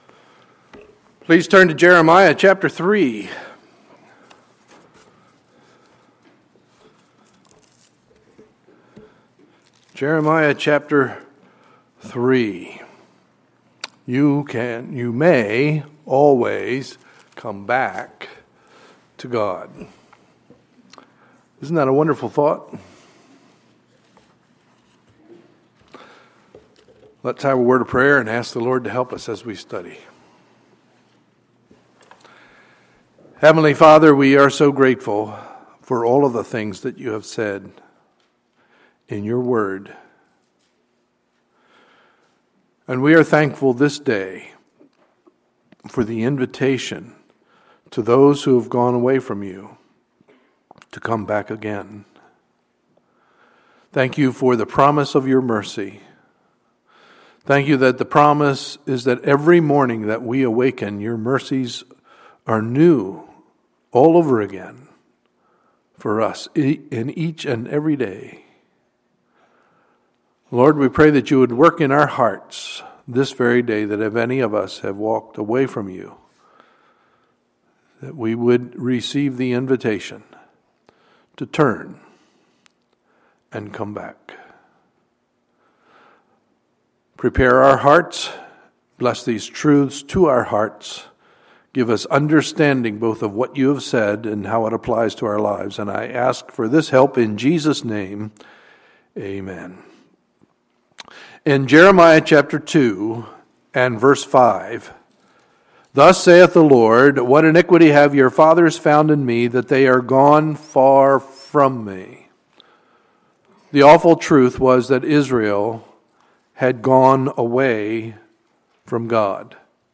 Sunday, January 25, 2015 – Sunday Morning Service